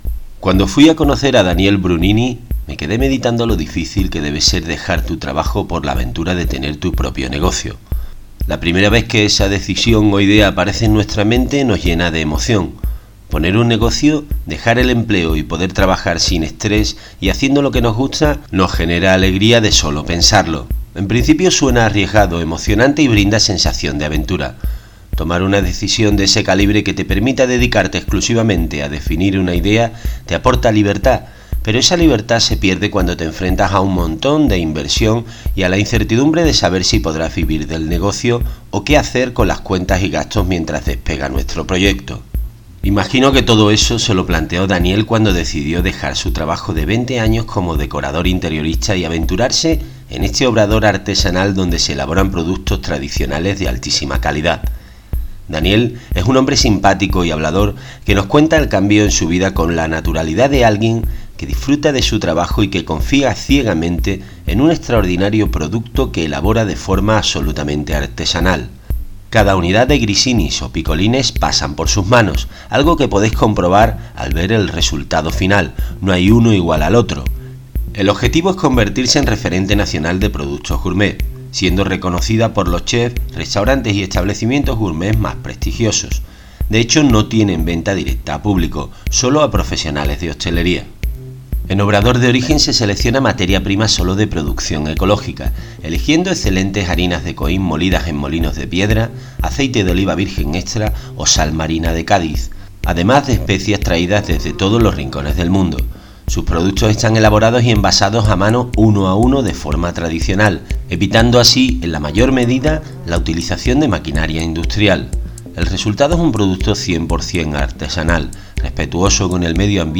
Aquí tenéis el post audiodescrito: